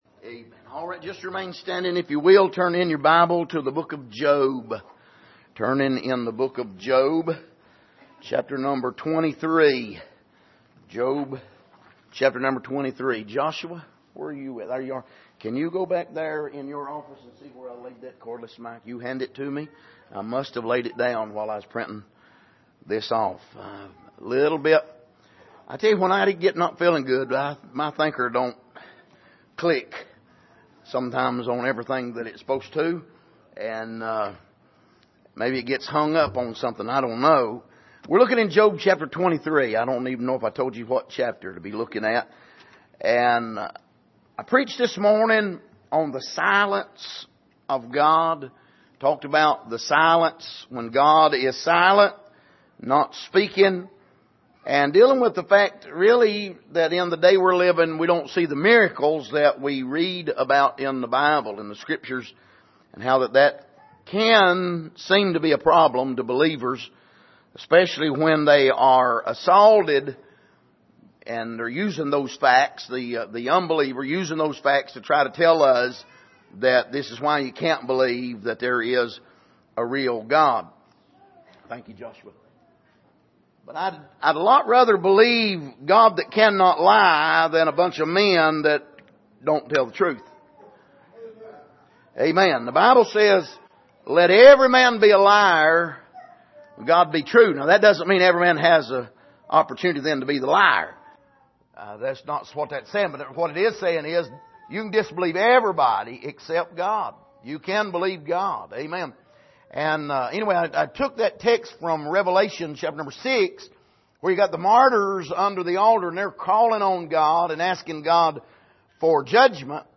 Passage: Job 23:9-10 Service: Sunday Evening